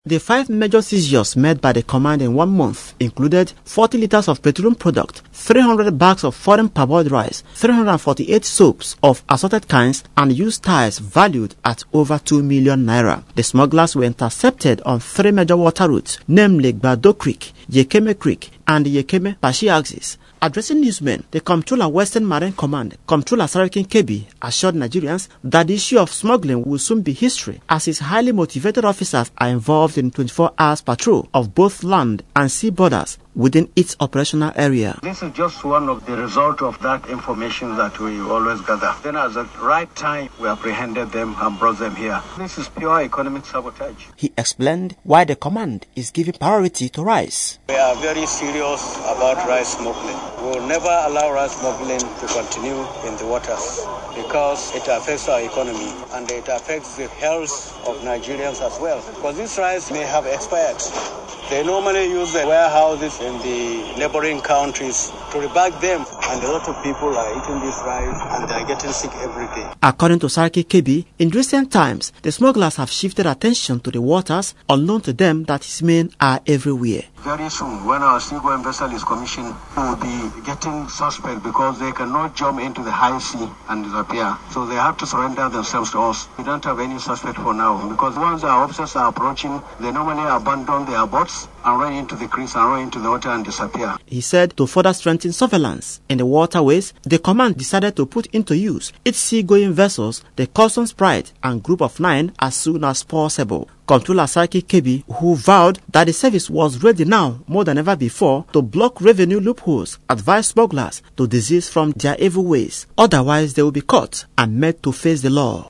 Customs Area Controller , Western Marine Command, Apapa Lagos , Comptroller Sarkin Kebbi, who made this known at a news briefing to announce major seizures made by the command, said adequate measure have have been put in place to ride both land and water routes of criminal elements involved in smuggling.